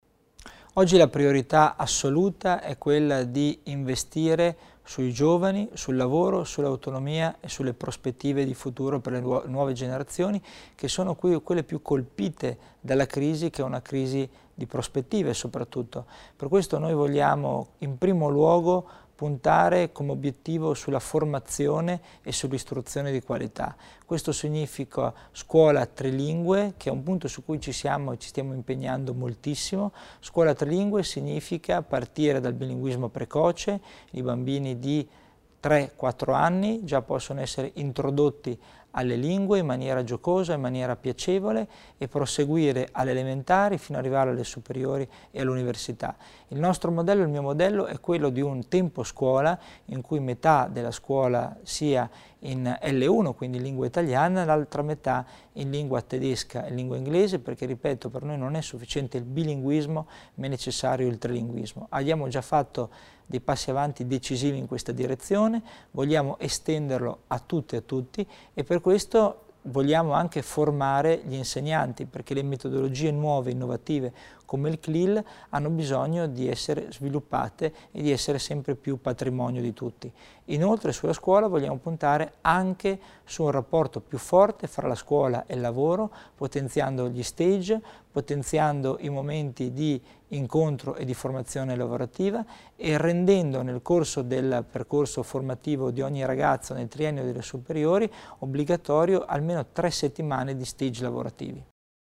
L'Assessore Tommasini spiega gli interventi nel settore scuola
Il trilinguismo e un maggior collegamento con il mondo del lavoro, ad esempio con stages per tutti i ragazzi delle superiori: sono questi secondo l’assessore provinciale Christian Tommasini i due fronti su cui continuare il lavoro fatto finora nella scuola. Tommasini ha anticipato i passi di fine legislatura e i progetti futuri oggi (1° agosto) a Bolzano nell’ambito dei Colloqui con i media.